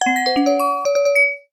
06762 hawaii game complete ding
complete ding game happy hawaii level levelup notification sound effect free sound royalty free Sound Effects